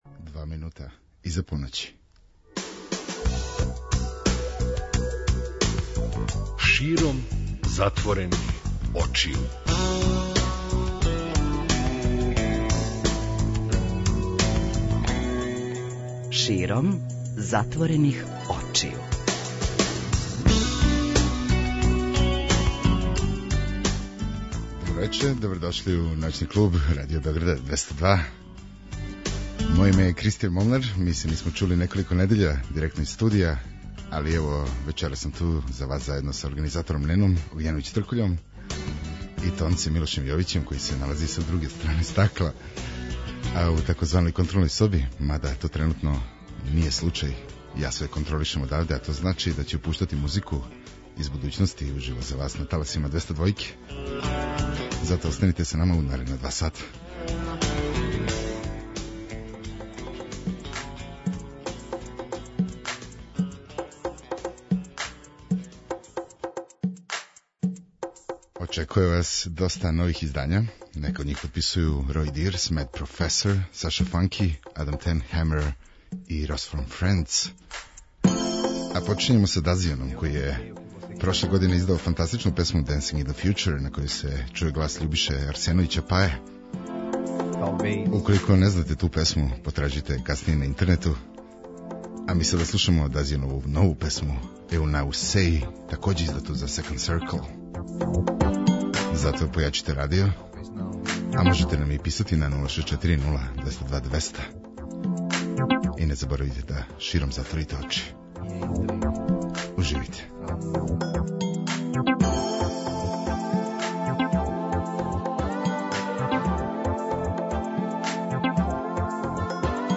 Ди-џеј
за вас бира музику ове ноћи